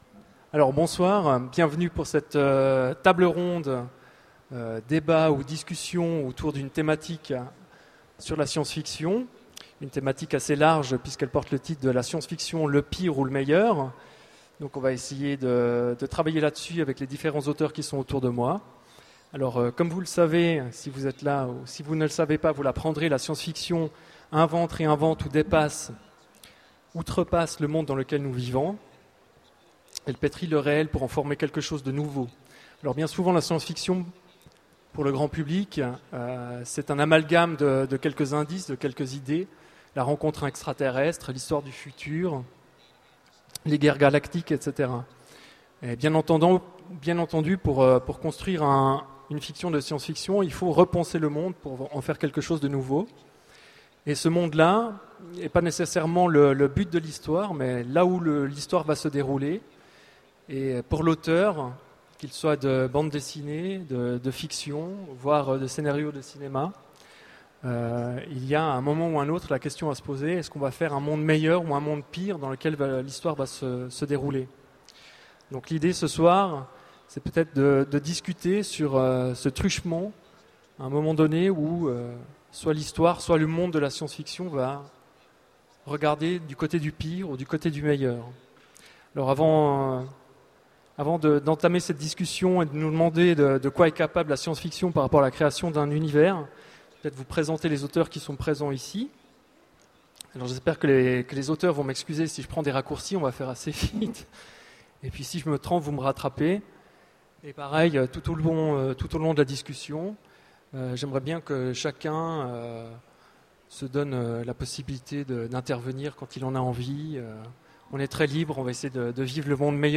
Utopiales 09 : Conférence La SF : le pire ou le meilleur ?